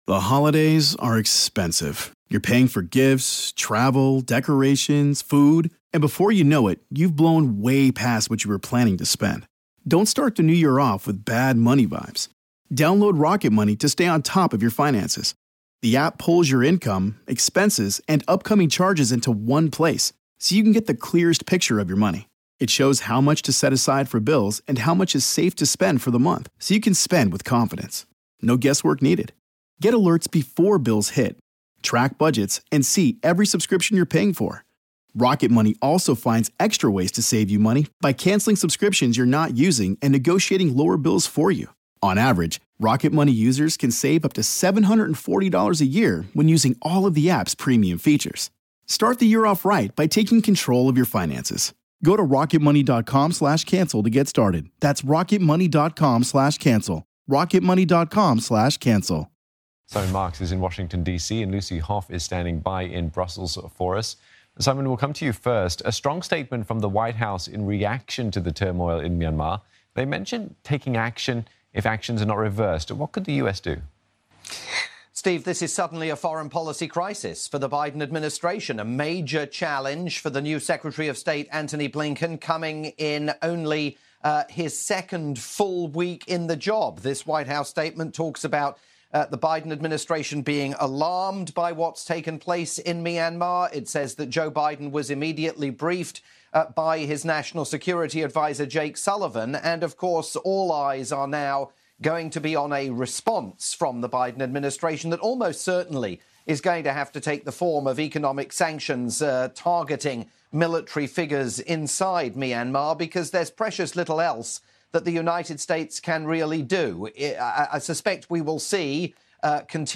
live report for CNA